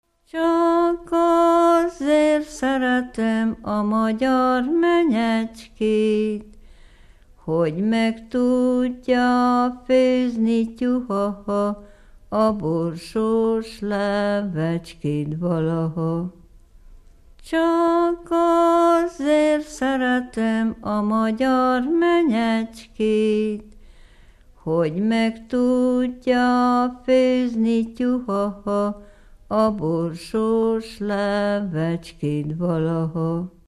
Dunántúl - Somogy vm. - Nemespátró
ének
Stílus: 5. Rákóczi dallamkör és fríg környezete